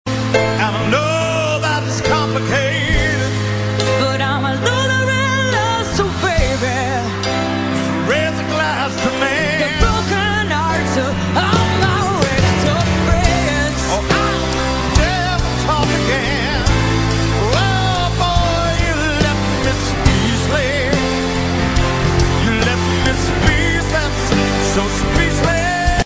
(Мелодия для мобильного)